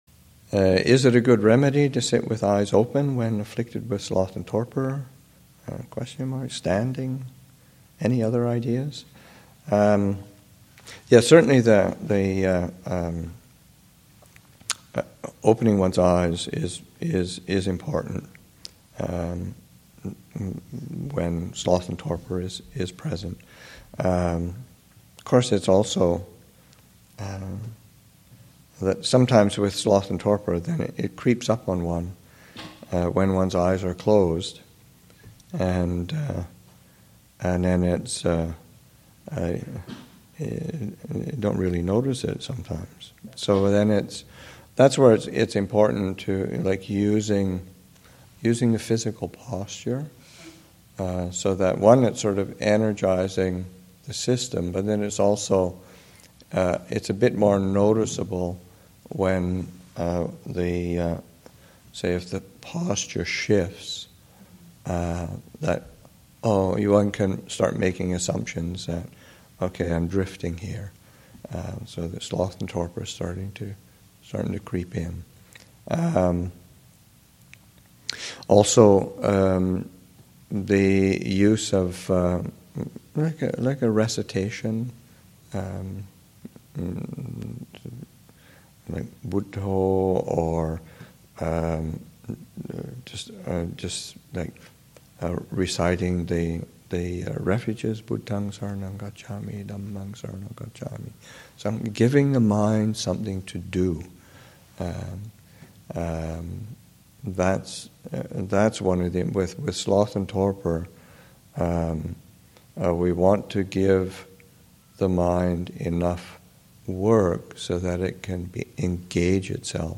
2014 Thanksgiving Monastic Retreat, Session 4 – Nov. 25, 2014